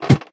sounds / step / ladder4.ogg
ladder4.ogg